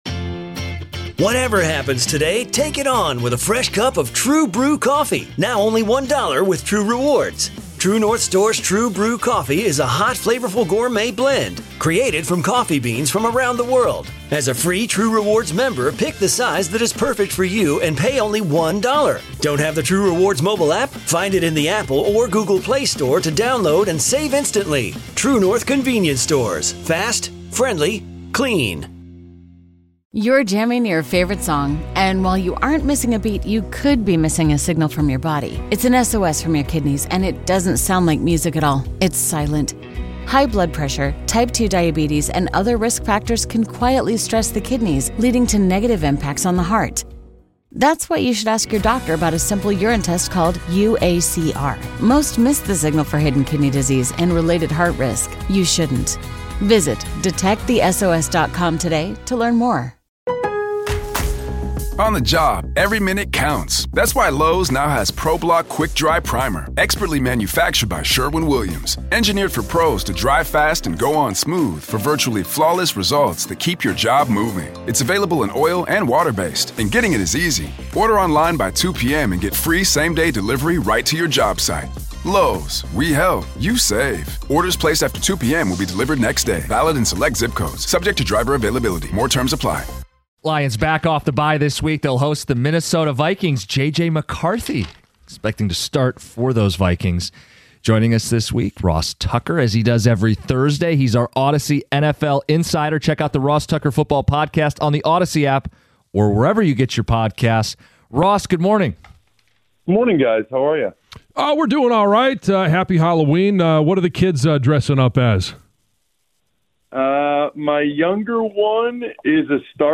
Ross Tucker joins us to preview Lions-Vikings and Bills-Chiefs